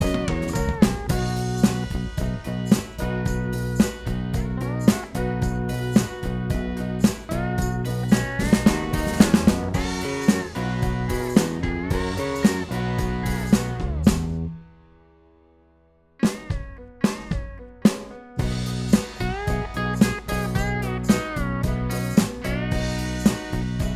Minus All Guitars Rock 3:43 Buy £1.50